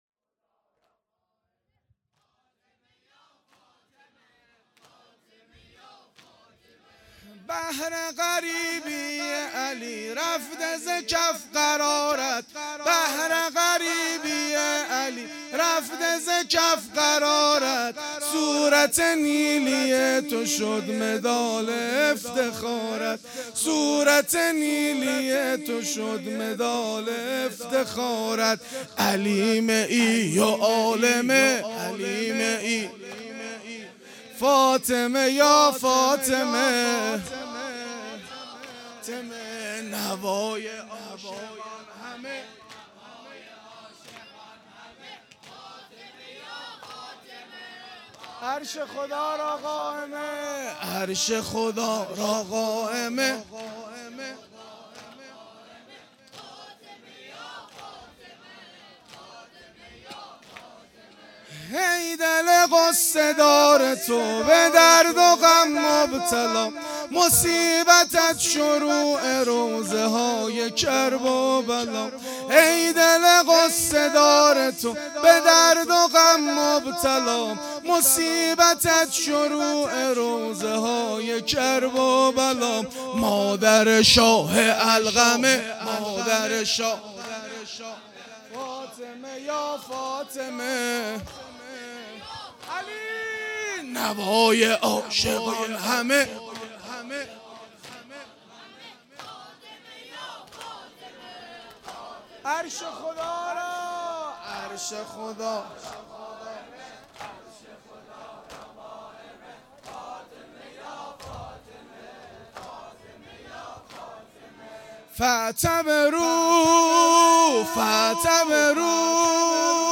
شام شهادت ، نوحه